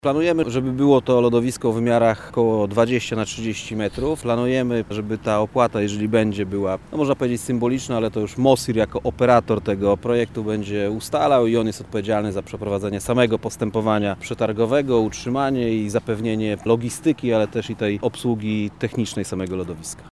Tomasz Fulara – mówi Tomasz Fulara, Zastępca Prezydenta Miasta Lublin ds. Inwestycji i Rozwoju.